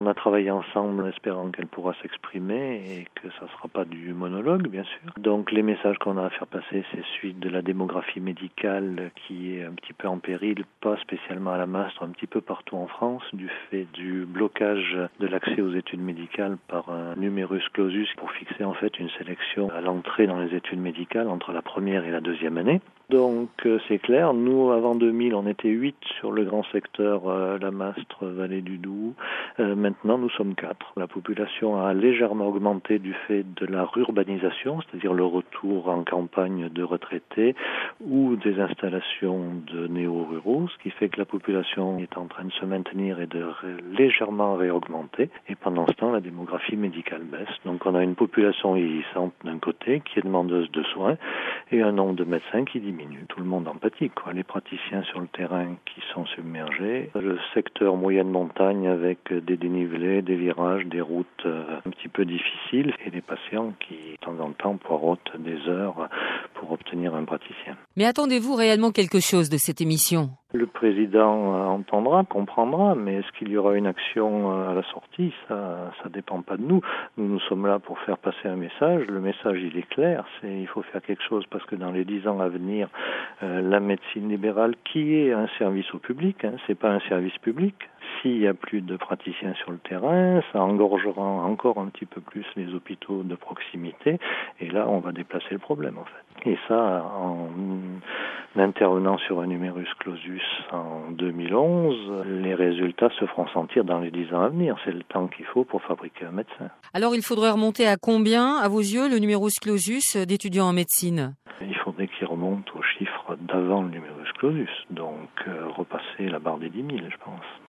Interview France Bleue – France Info